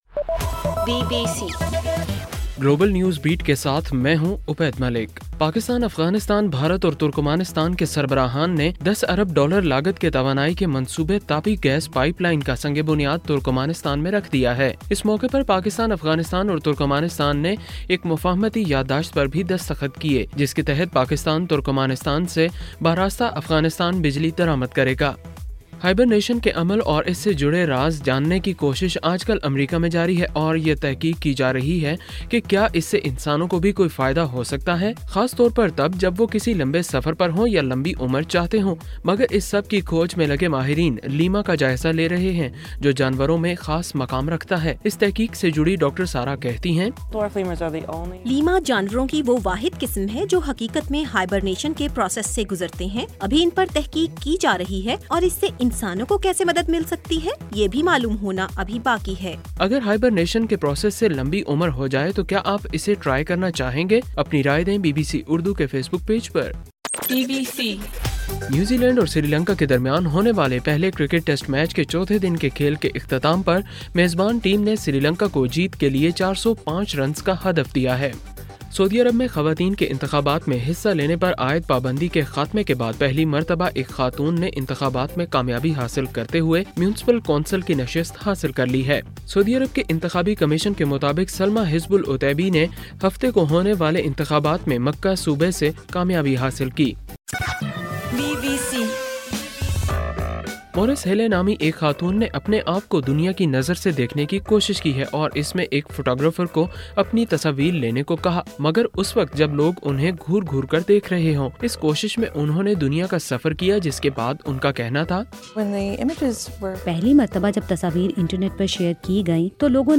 دسمبر 13: رات 9 بجے کا گلوبل نیوز بیٹ بُلیٹن